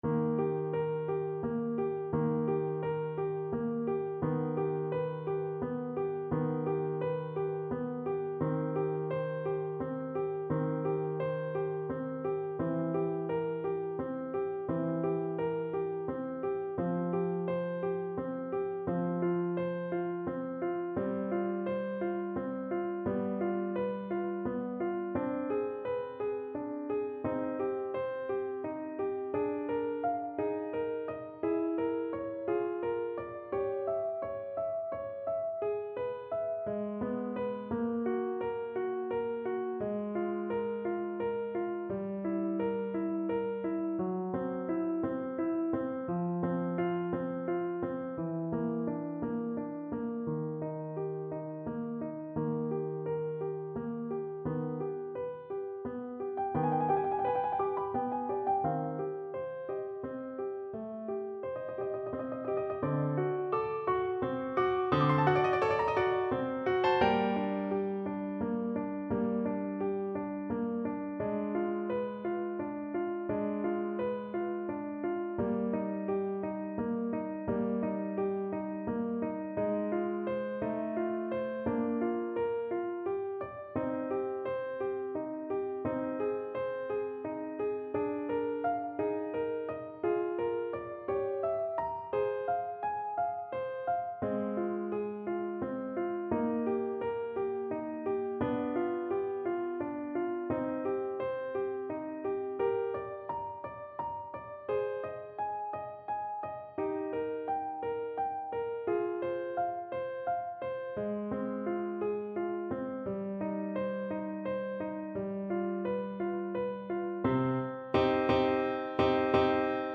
Alto Saxophone version
Free Sheet music for Alto Saxophone
6/8 (View more 6/8 Music)
G4-G6
Classical (View more Classical Saxophone Music)